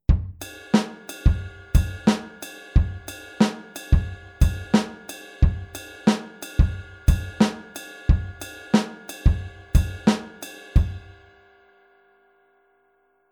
Außerdem könnte auch der letzte Kick auf 3+ lustig werden.
Wenn du nun glaubst diese offbeat-Version spielen zu können, sollte sie ähnlich dieser hier klingen:
Groove02-4off.mp3